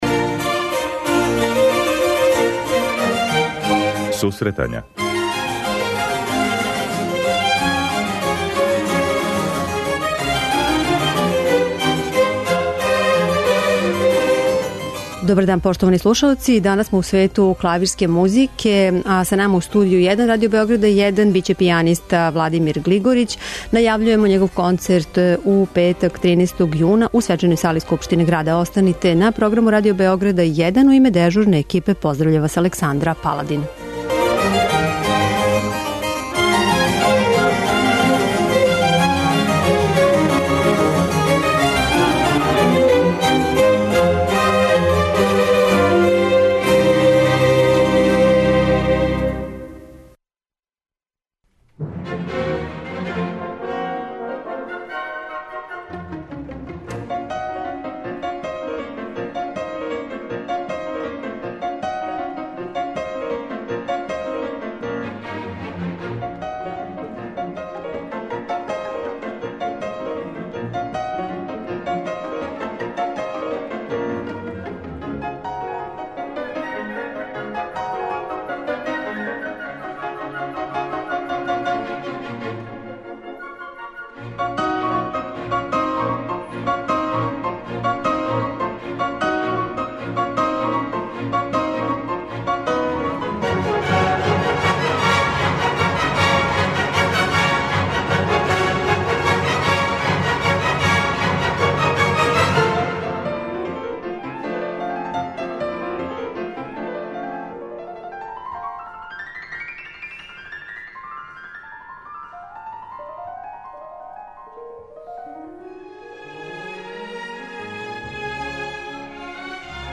преузми : 26.34 MB Сусретања Autor: Музичка редакција Емисија за оне који воле уметничку музику.